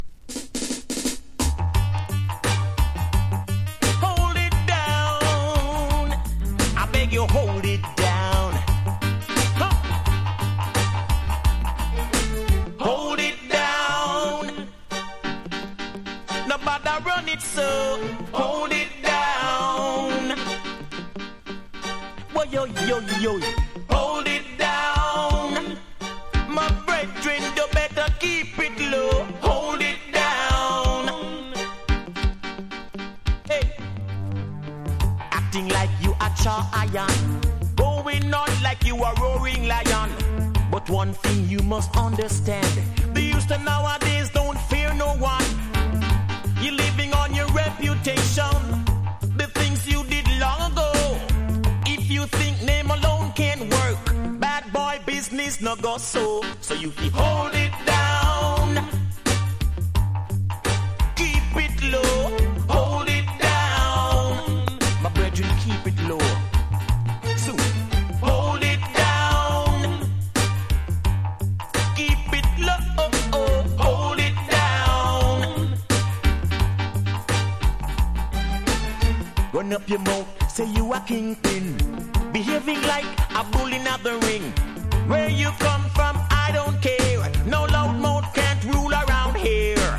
• REGGAE-SKA
DANCE HALL